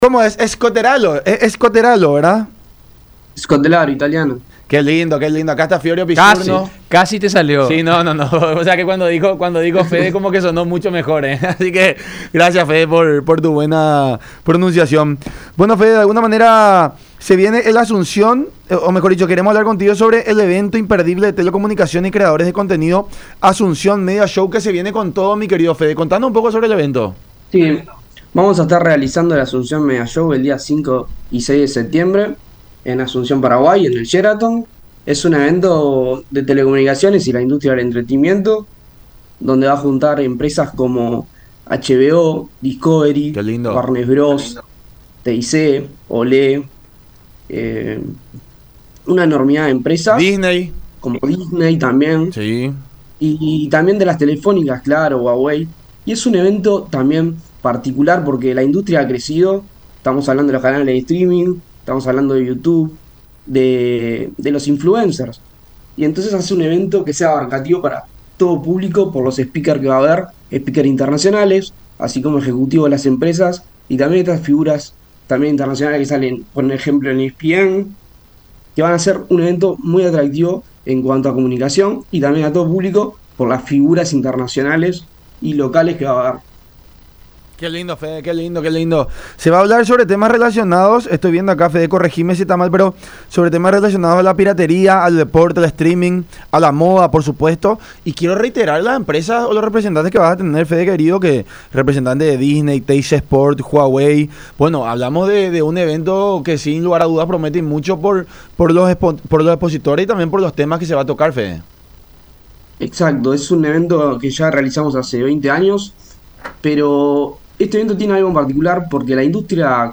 “La industria ha cambiado y se hace uno mas masivo y no tan de nicho, se hablaran temáticas como el 5g o hacia donde irá la televisión” , agregó en el programa “La Unión Hace La Fuerza” por Unión TV y radio La Unión.